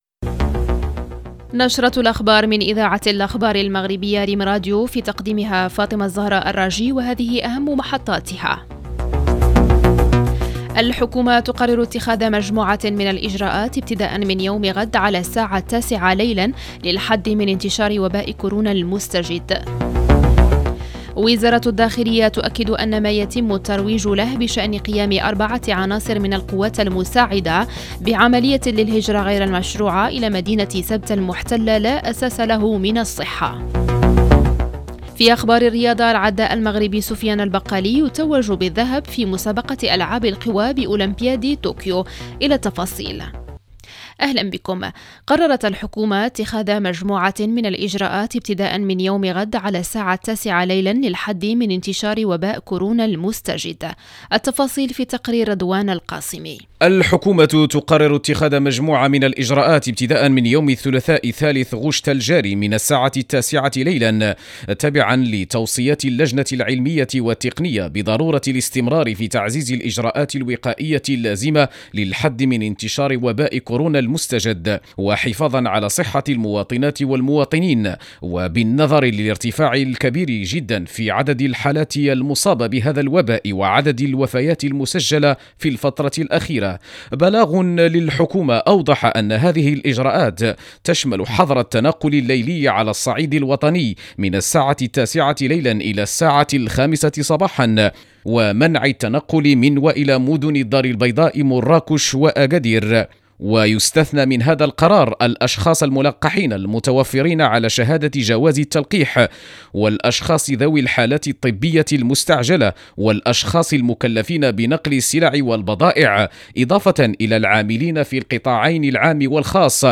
Bulletins d'information